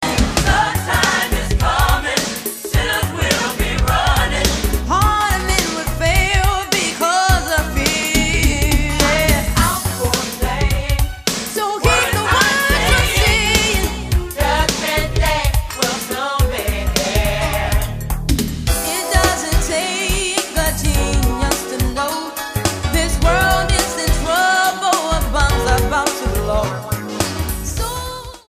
STYLE: Classical
soprano